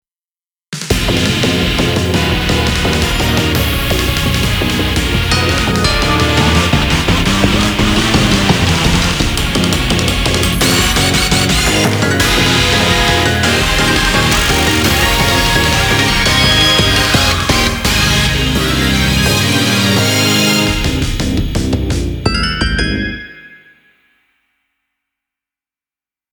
ダークが色濃いシリーズです。
EX-inst